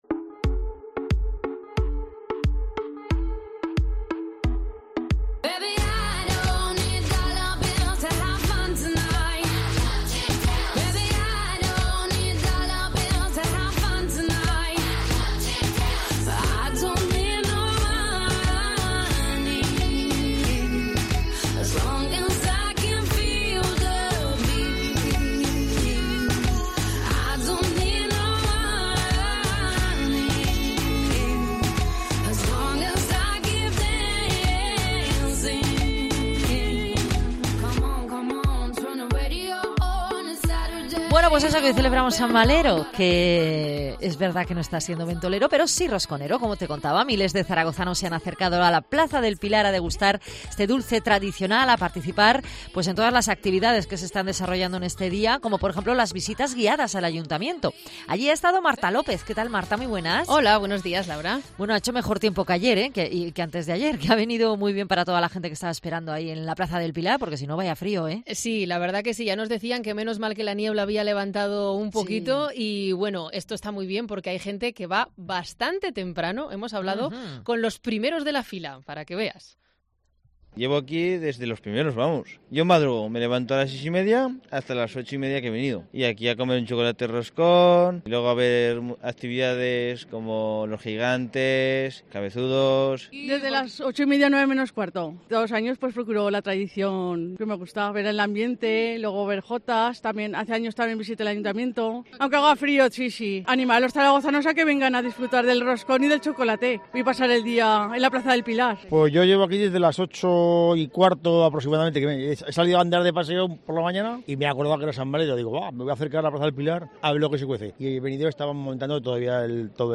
San Valero en la calle: escucha aquí todos los sonidos del día del patrón de Zaragoza
REPORTAJE
El tradicional reparto de roscón en la Plaza del Pilar ha sido uno de los platos fuertes de la celebración de San Valero, patrón de Zaragoza. Las visitas guiadas al Ayuntamiento de Zaragoza, la confección del escudo floral de la ciudad, el homenaje a los voluntarios o las actividades infantiles forman parte de esta jornada festiva. Escucha aquí todos los sonidos de los protagonistas.